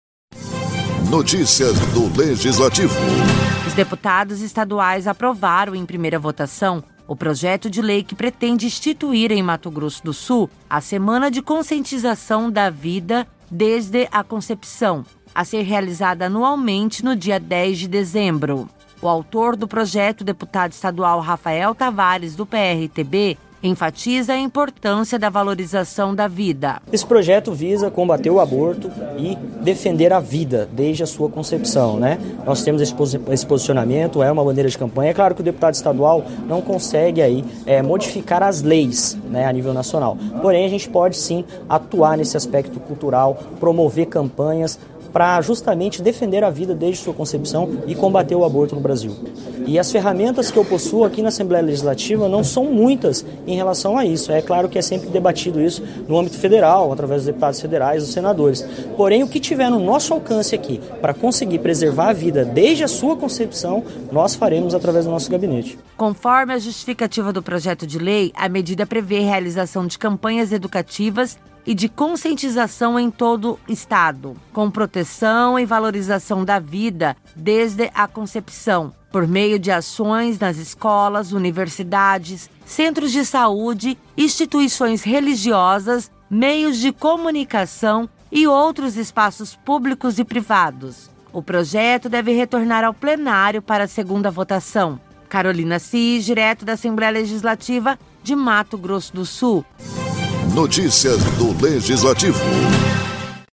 A deputada Mara Caseiro (PSDB) usou a tribuna da ALEMS, durante sessão ordinária, para lamentar a morte de mais duas mulheres, que foram vítimas de feminicídio, em Campo Grande. A parlamentar destacou que esse cenário de violência tem preocupado as autoridades, e que somente no ano de 2023 já foram registrados 11 casos de feminicídio em Mato Grosso do Sul.